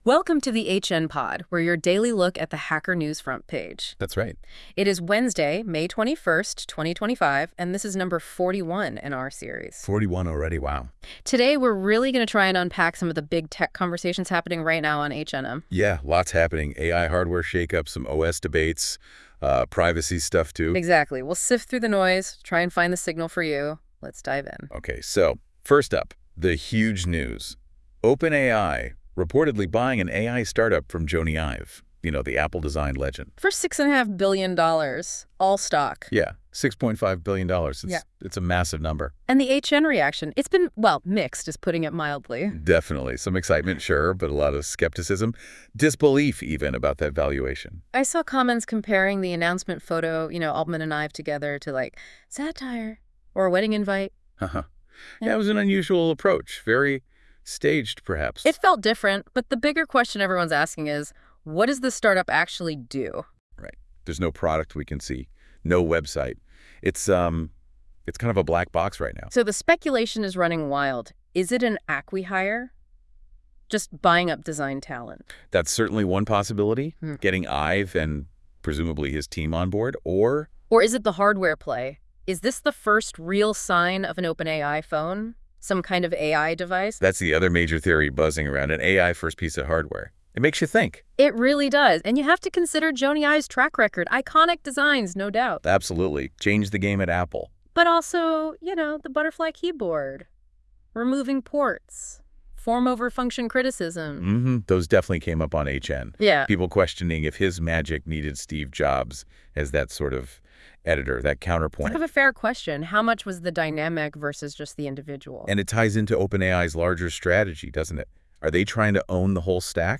This episode is generated by 🤖 AI.